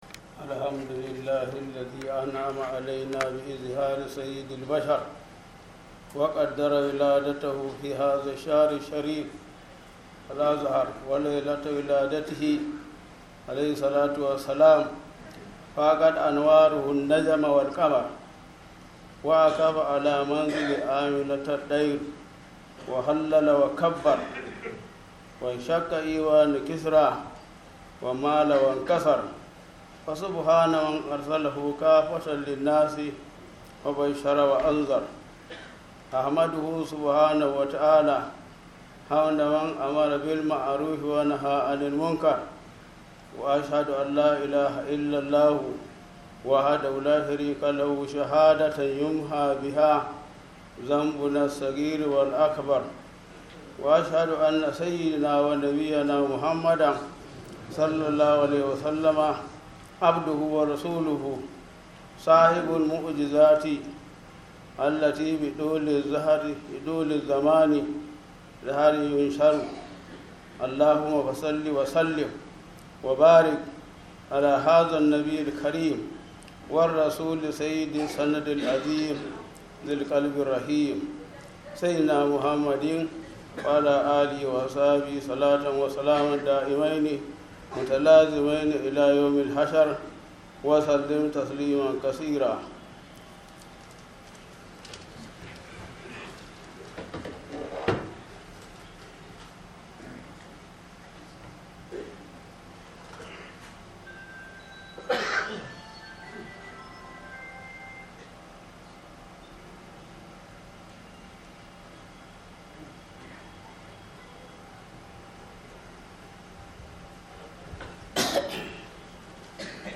KHUDBAH JUMA-A